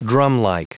Prononciation du mot drumlike en anglais (fichier audio)
Prononciation du mot : drumlike